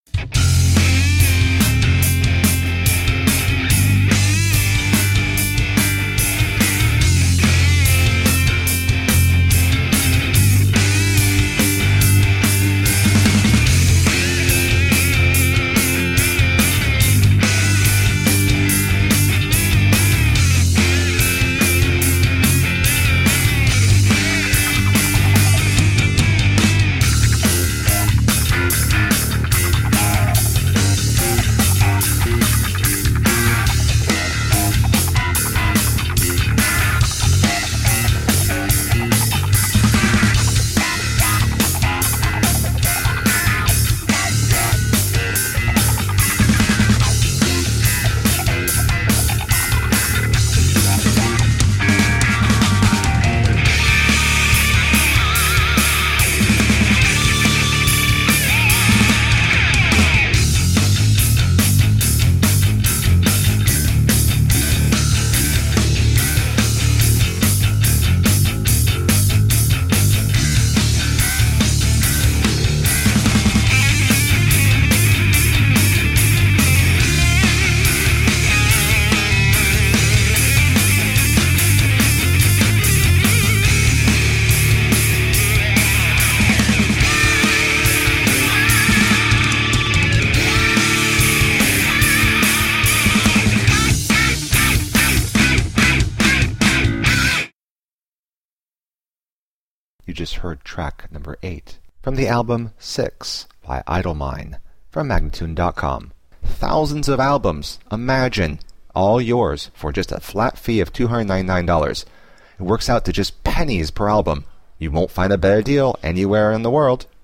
A solid hybrid sound of instrumental rock.
Tagged as: Hard Rock, Rock, Metal, Instrumental